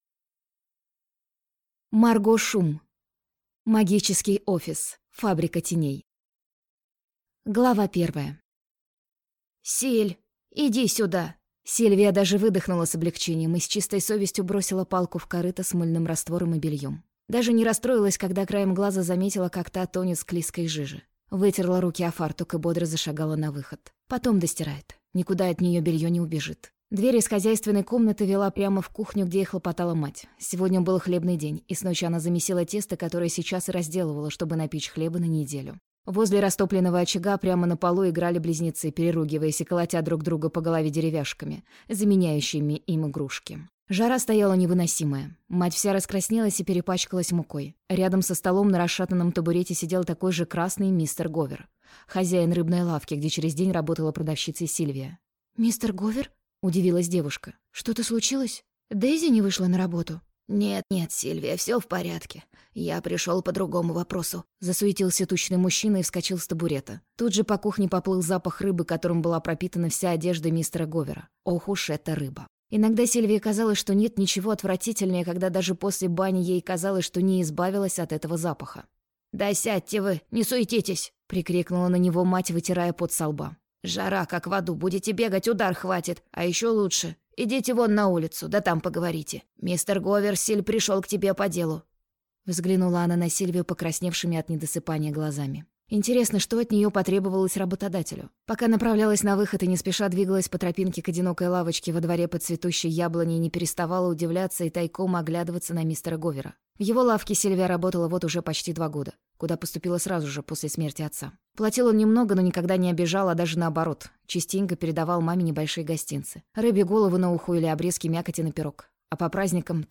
Аудиокнига Магический офис. Фабрика теней | Библиотека аудиокниг